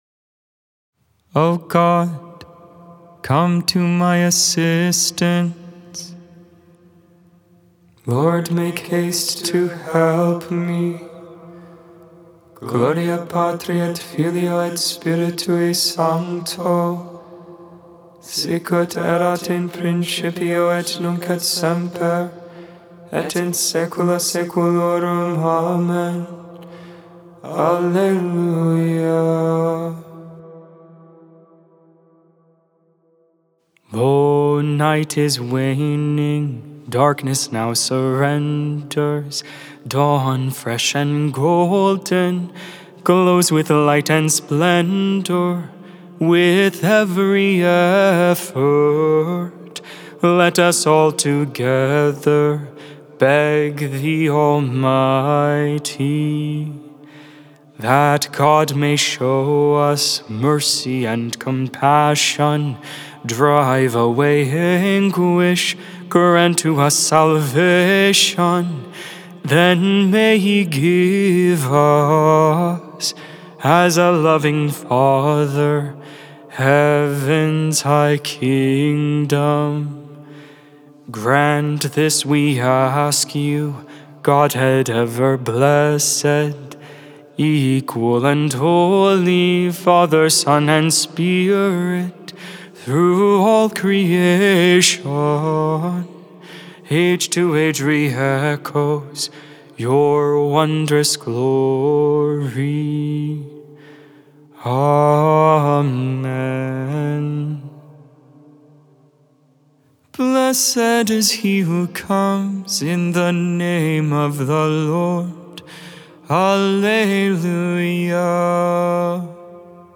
Divine Office Hymnal #252, Mode IV, melody 74.